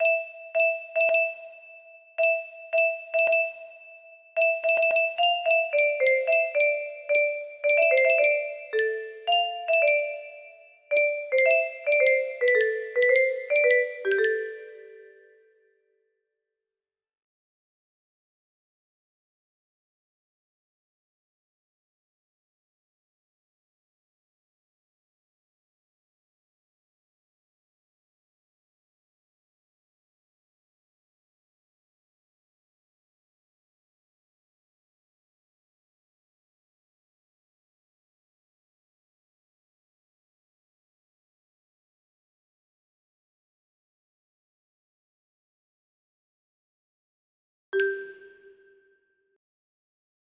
Mehter Marsi music box melody